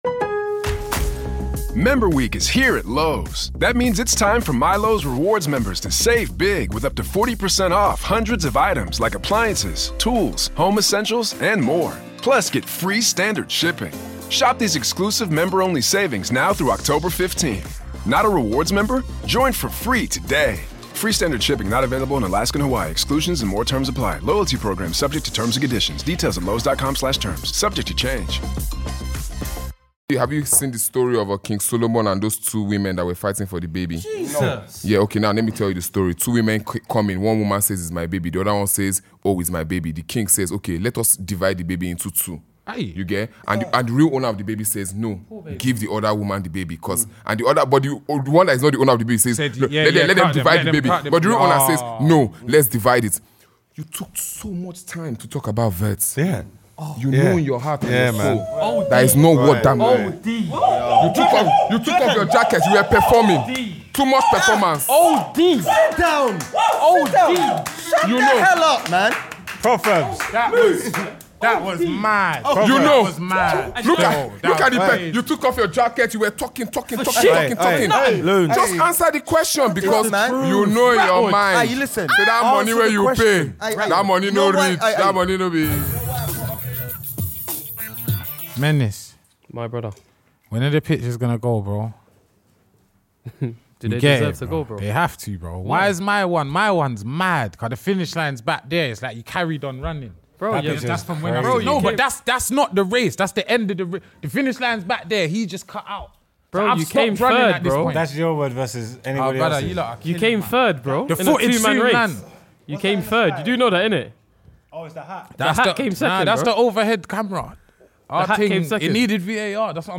NIGERIA’S OWN ODUMODUBLVCK PULLED UP TO FILTHY HQ TO CHOP IT UP WITH THE CROWD.